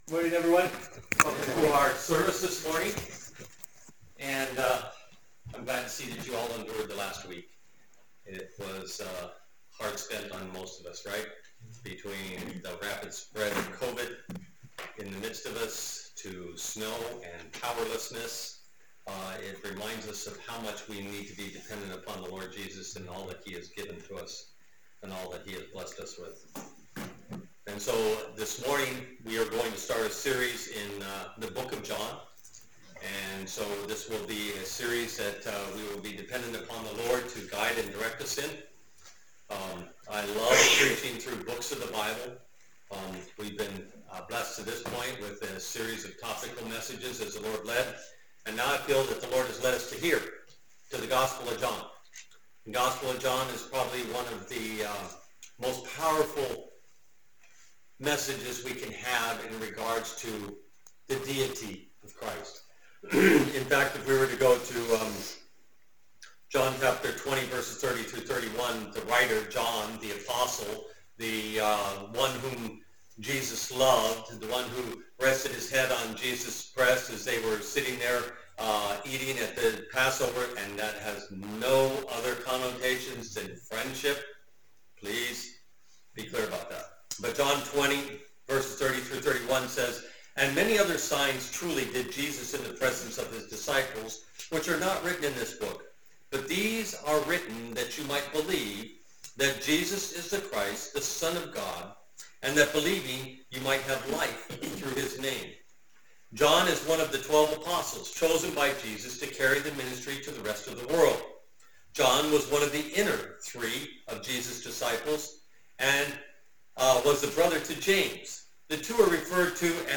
All Sermons Behold His Glory